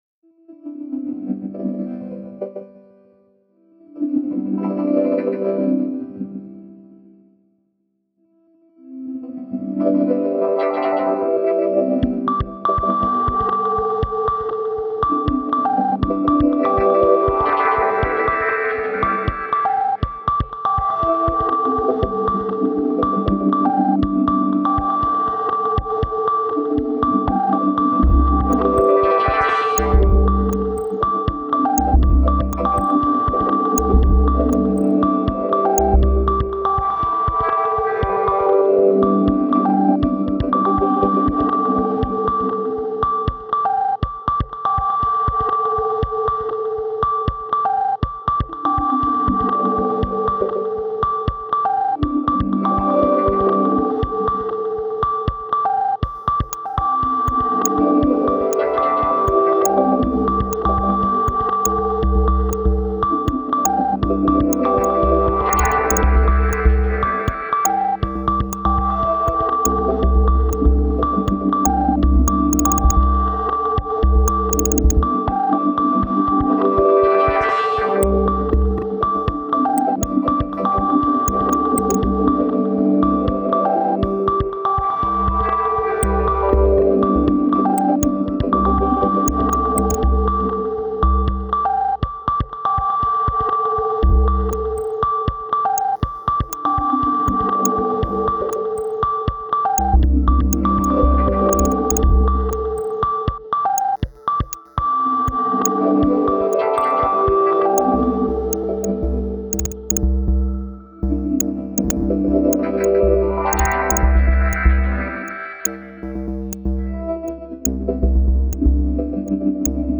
downbeat style